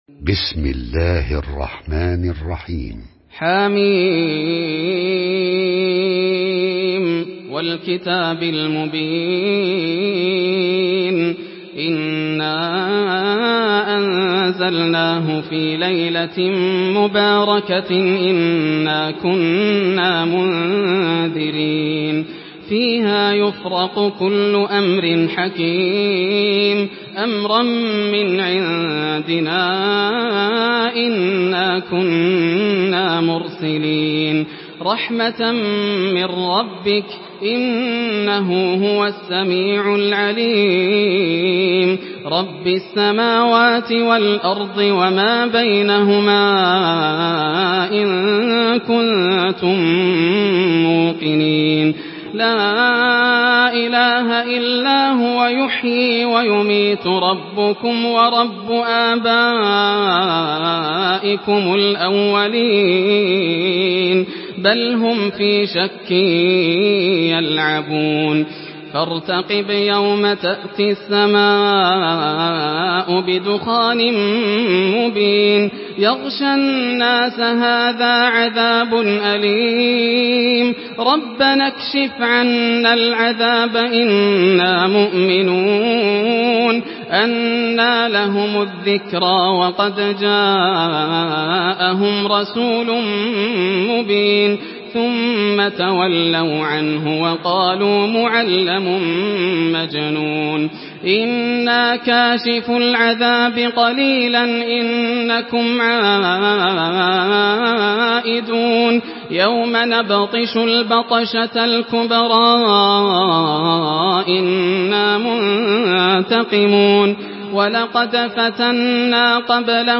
Surah Ad-Dukhan MP3 by Yasser Al Dosari in Hafs An Asim narration.
Murattal